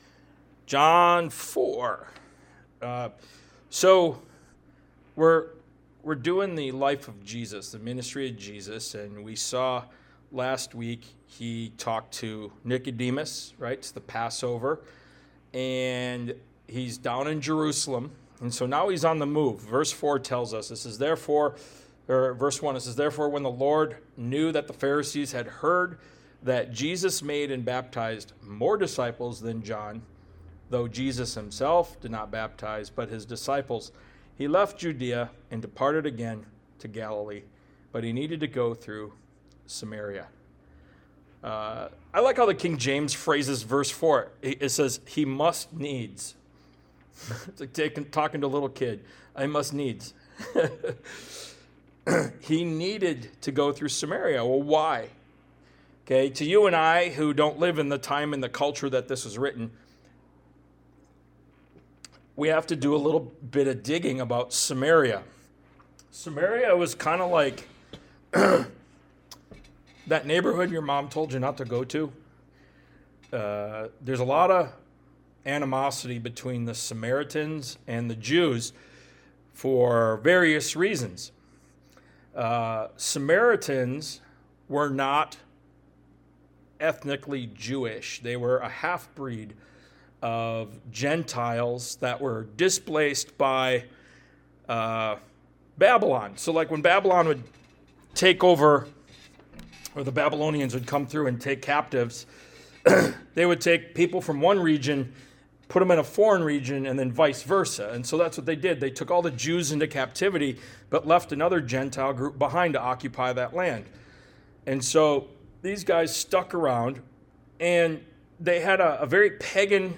Ministry of Jesus Service Type: Sunday Morning « “The Four Musts” The Ministry of Jesus Part 6 “Faith Lift” Ministry of Jesus Part 8 »